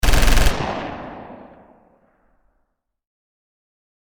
Download Machine Gun sound effect for free.
Machine Gun